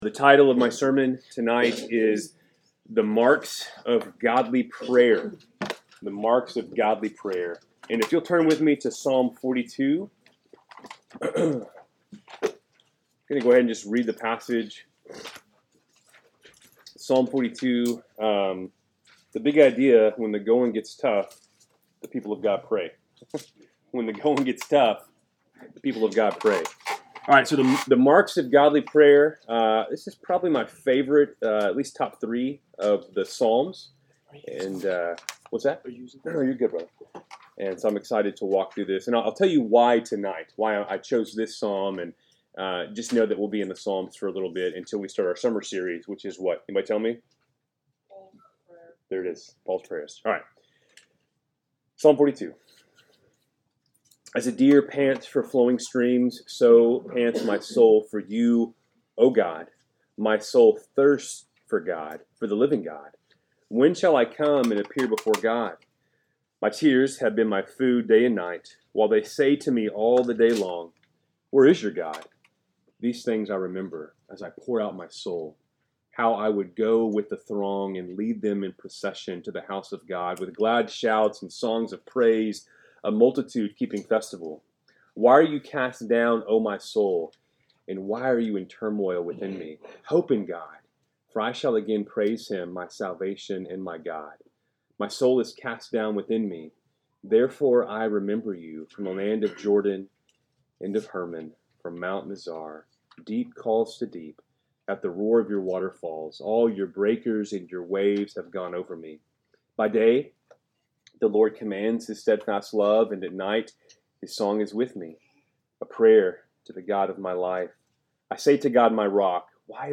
Wednesday Night Bible Study, April 23, 2025